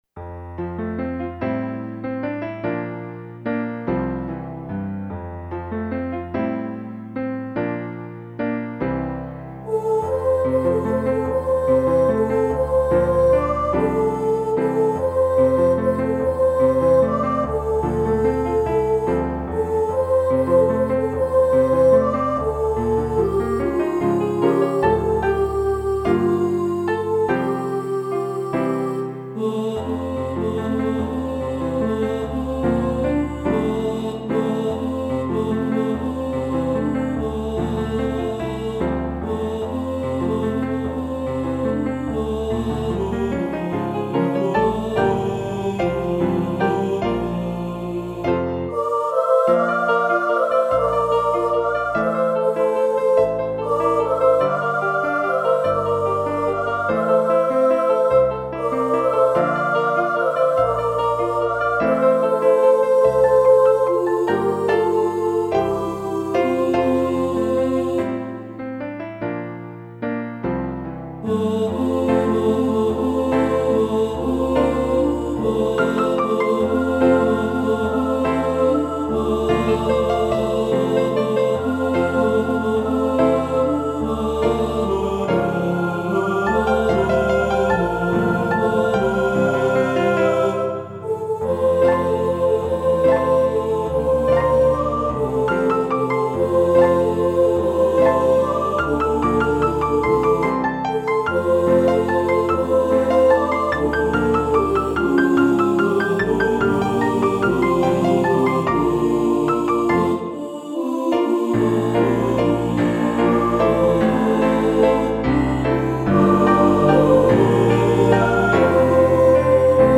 For Choir and Piano